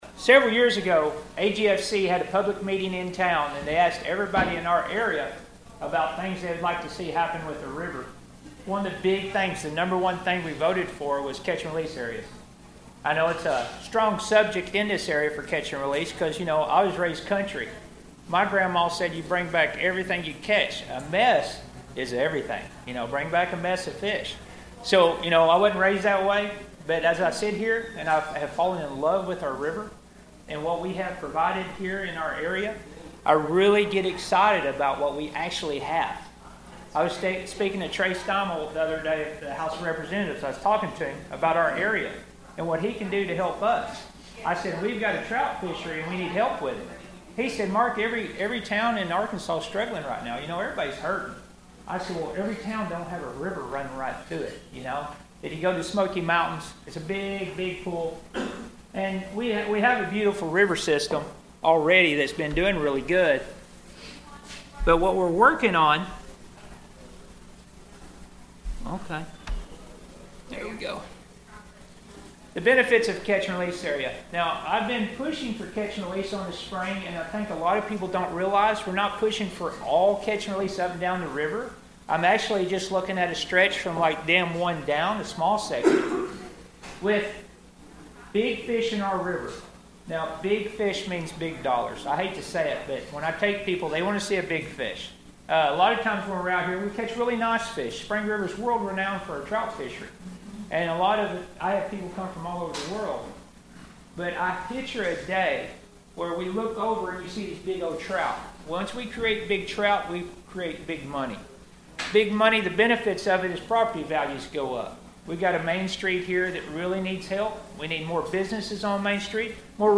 Thayer Mammoth Spring Rotary met Wednesday